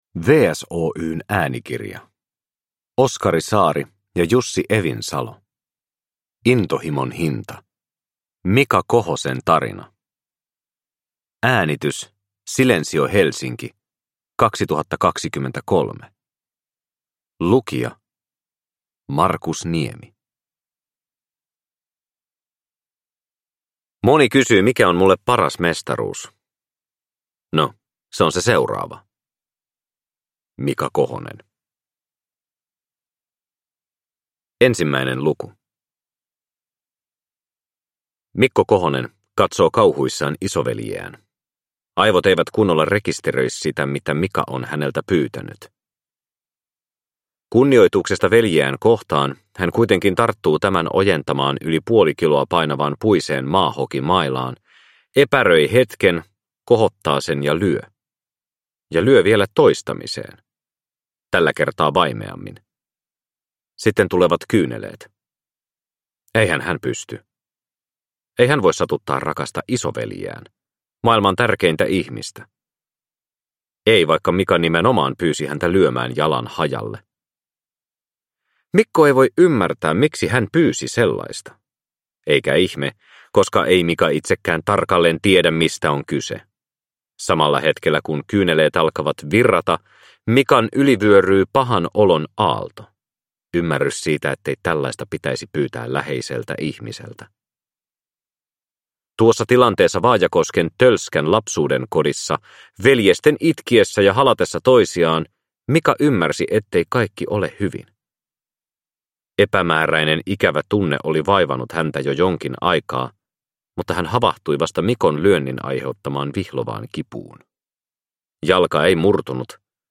Intohimon hinta - Mika Kohosen tarina – Ljudbok – Laddas ner